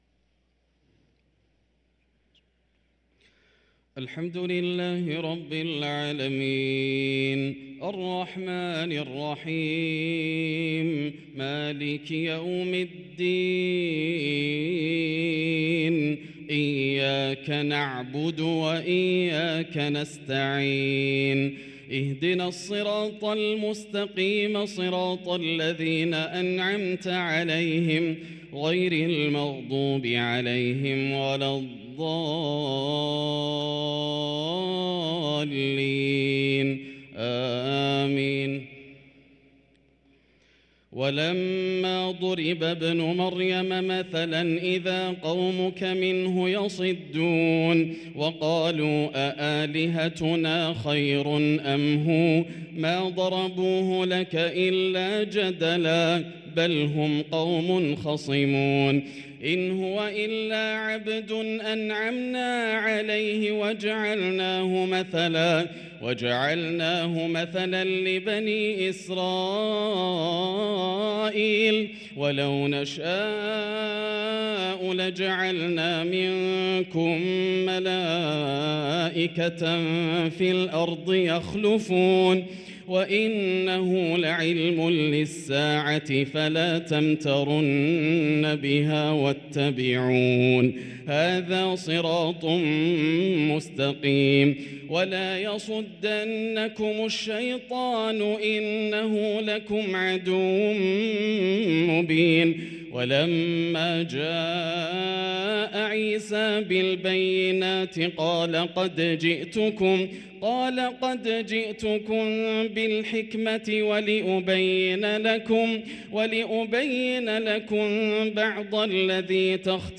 صلاة العشاء للقارئ ياسر الدوسري 26 شعبان 1444 هـ
تِلَاوَات الْحَرَمَيْن .